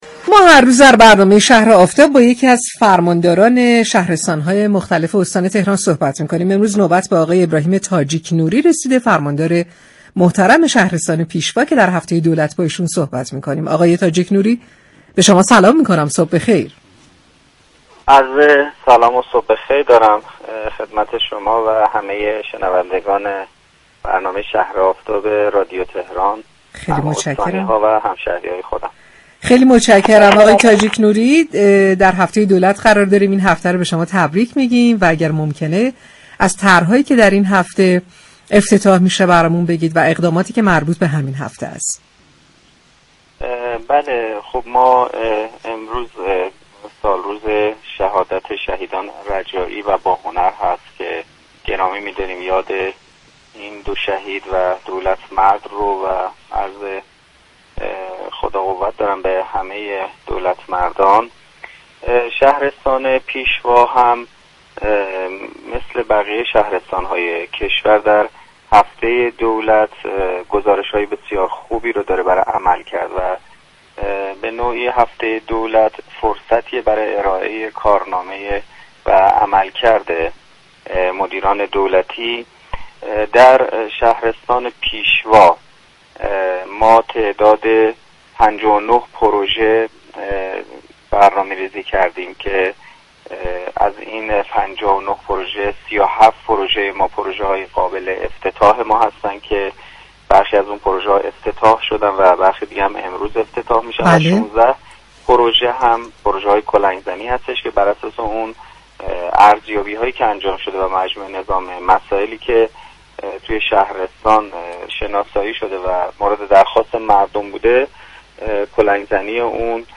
به گزارش پایگاه اطلاع رسانی رادیو تهران، ابراهیم تاجیك نوری فرماندار شهرستان پیشوا در گفت و گو با «شهر آفتاب» اظهار داشت: همزمان با هفته دولت 59 پروژه در شهرستان پیشوا افتتاح و به بهره‌برداری می‌رسد.